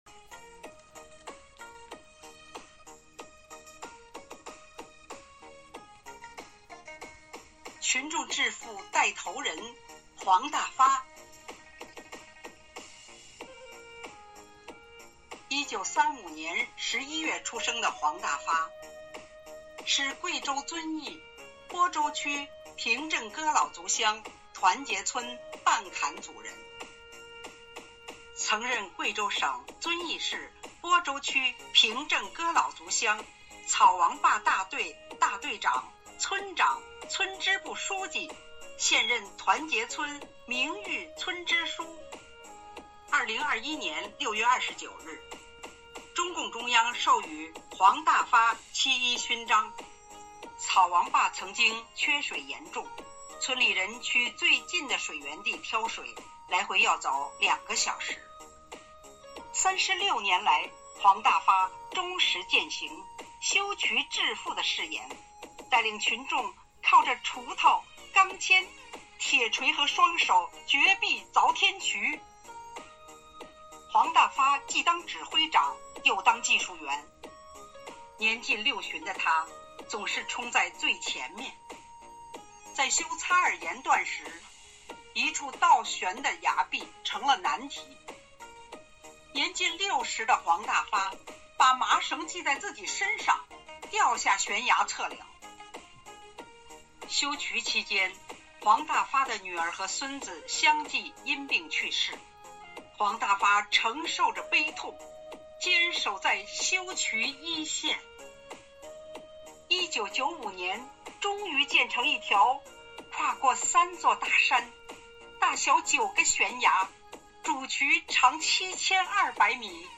五一劳动节来临之际，为致敬最美劳动者，4月28日，生活好课堂幸福志愿者魅力之声朗读服务（支）队举办“致敬最美劳动者 一一我心中的故事”云朗诵会。